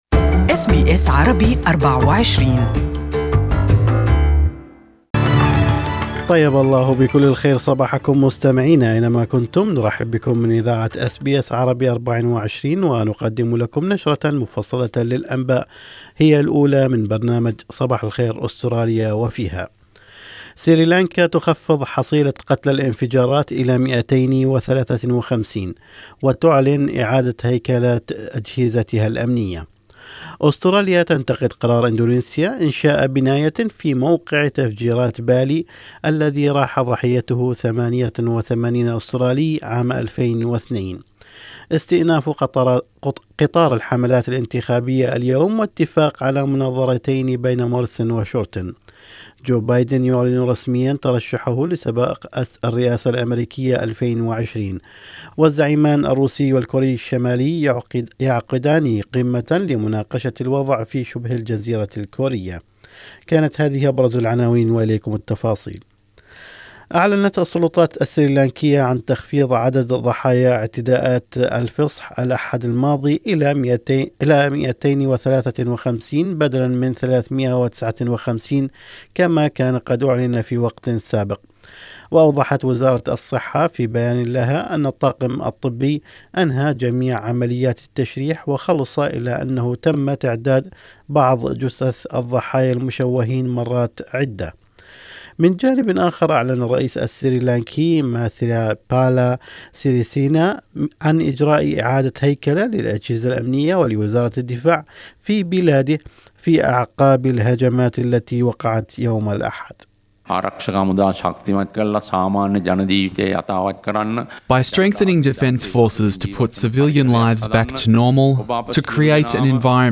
النشرة المفصلة للانباء لهذا الصباح باللغة العربية